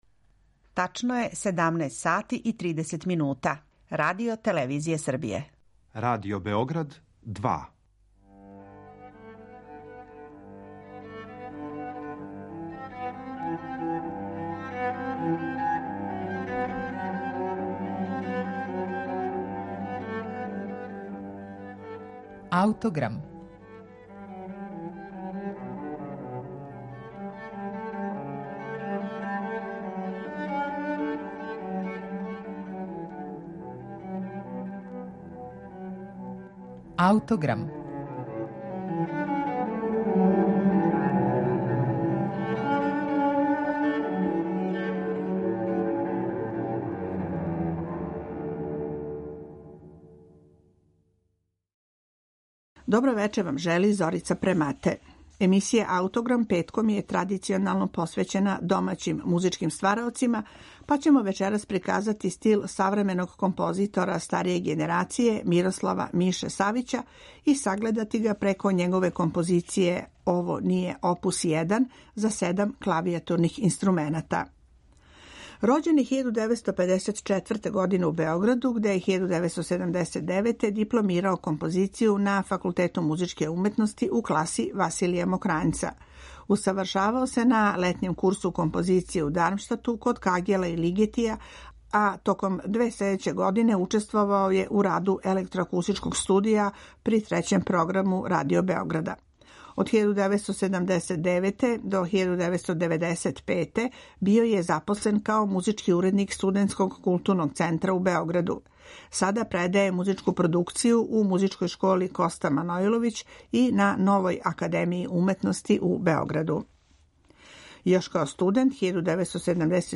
за седам клавијатурних инструмената
снимак је остварен на концерту